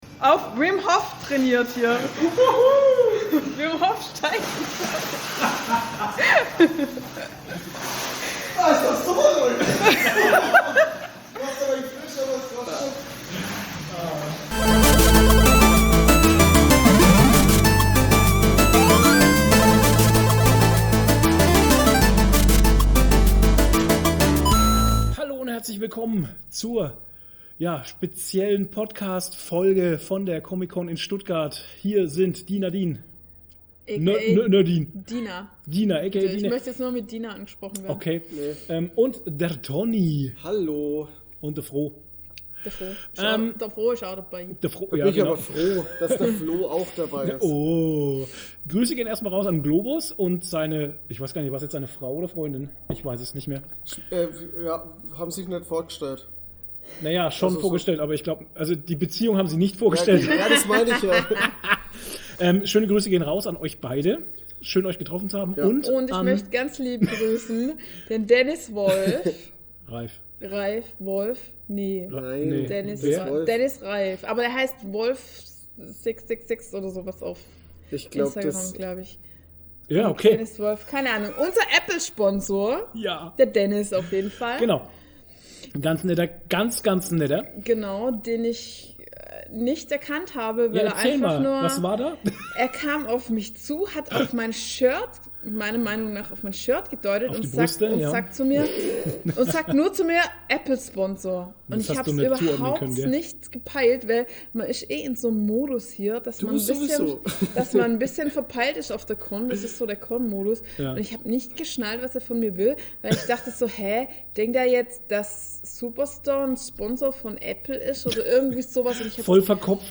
Hey ho, wir waren wieder auf der Comic Con in Stuttgart und berichten live von unseren Erlebnissen.